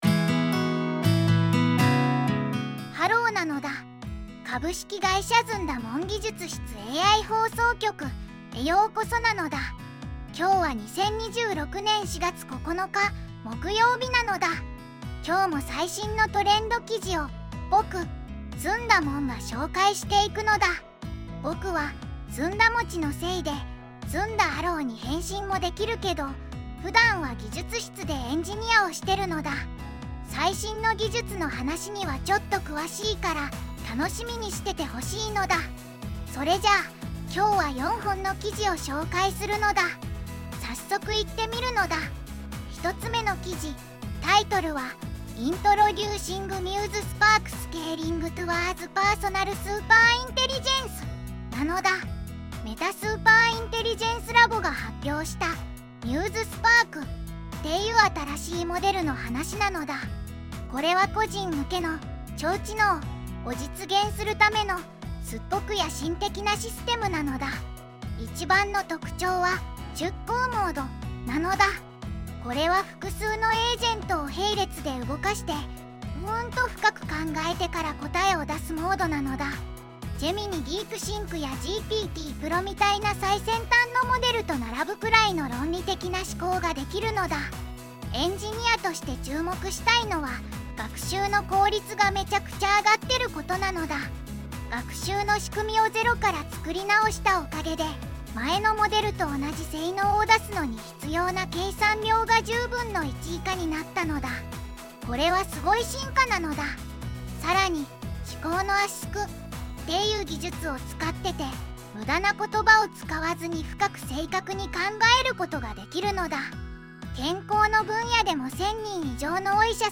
ずんだもん